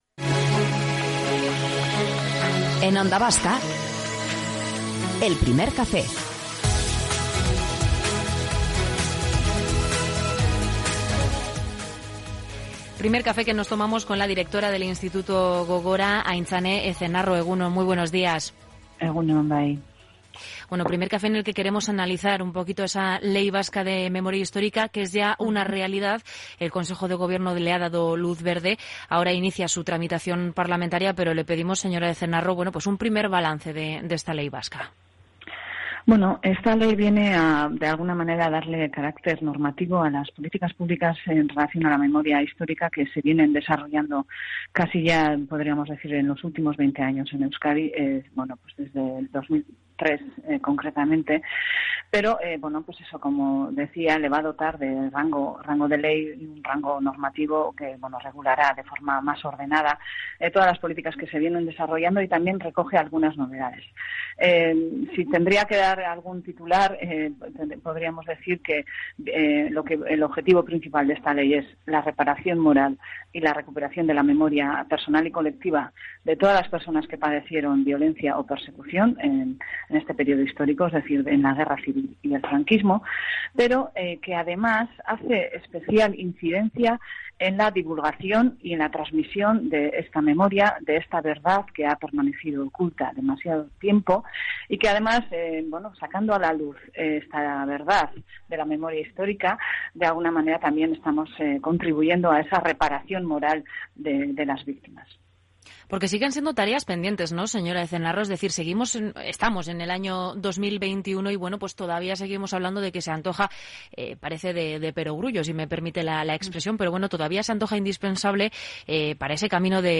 Entrevista íntegra a Aintzane Ezenarro, directora del Instituto Gogora - Onda Vasca
Morning show conectado a la calle y omnipresente en la red.